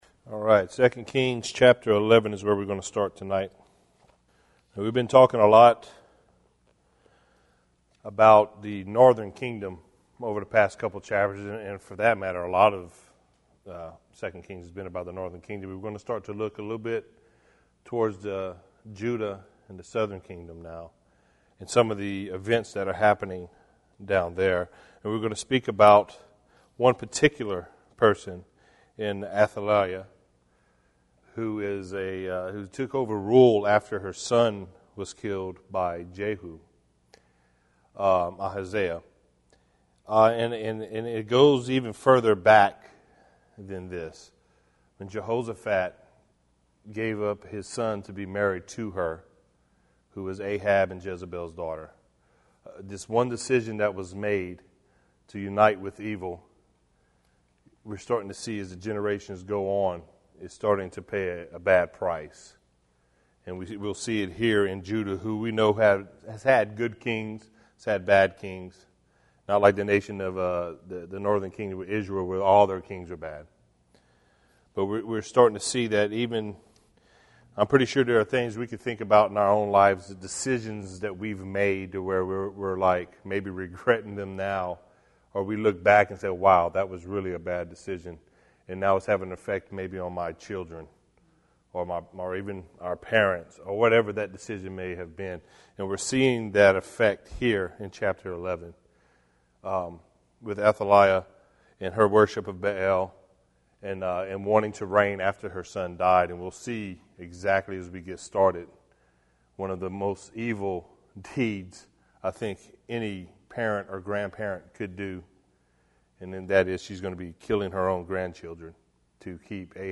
Join us for this verse by verse study in the book of 2 Kings